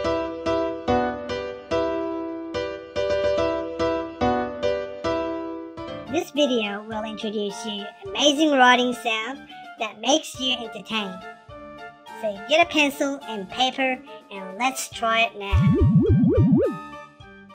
Música Clasica